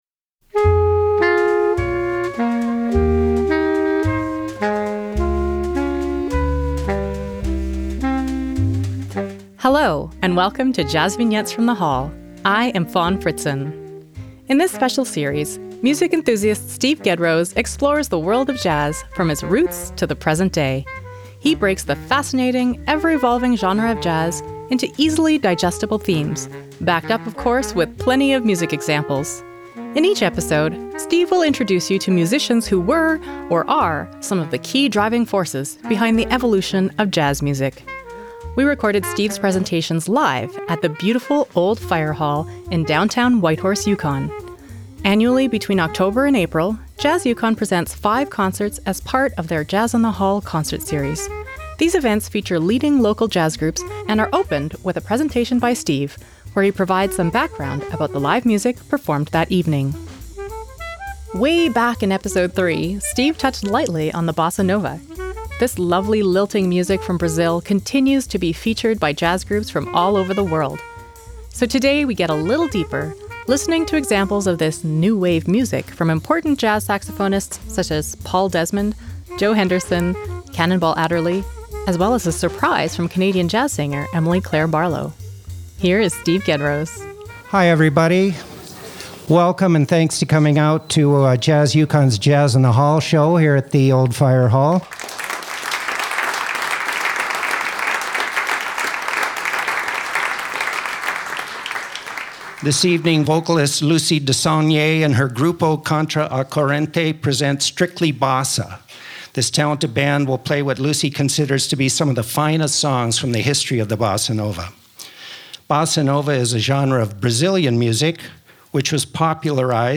Jazz Vignettes from the Hall - Episode 23 - Bossa Nova
jvfth23BossaNova.mp3